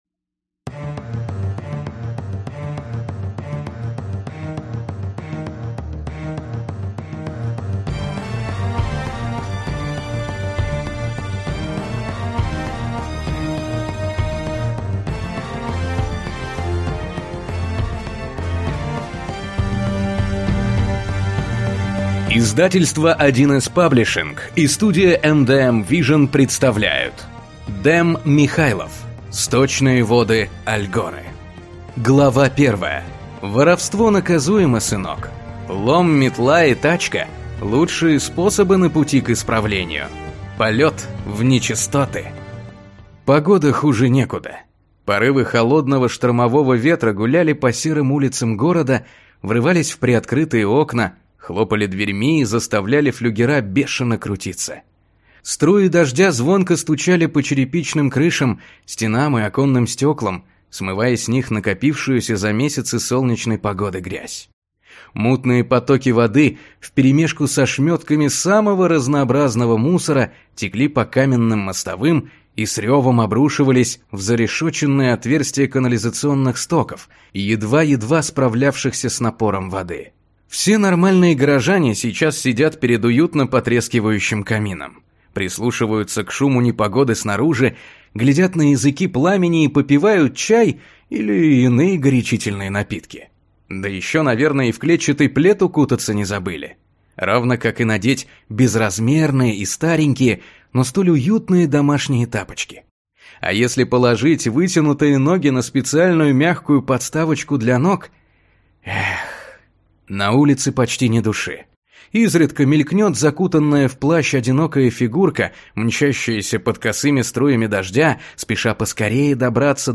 Аудиокнига Сточные воды Альгоры - купить, скачать и слушать онлайн | КнигоПоиск